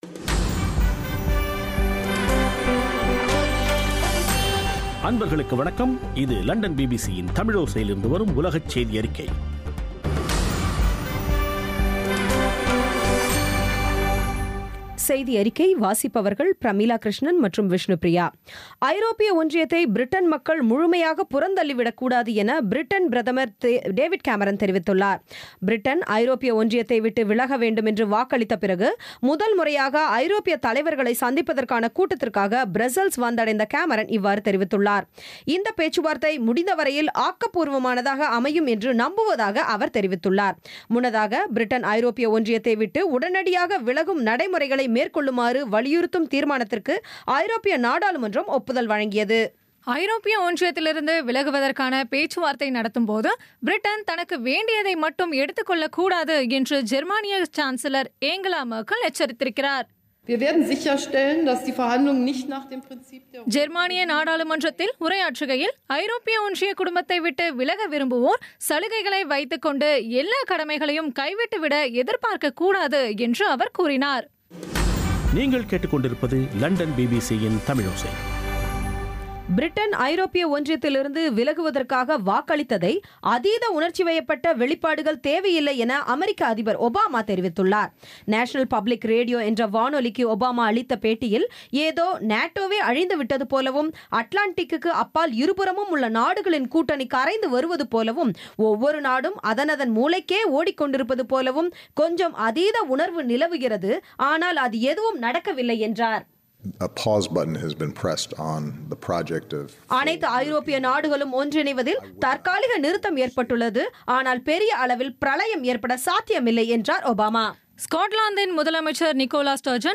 பி பி சி தமிழோசை செய்தியறிக்கை (28/06/16)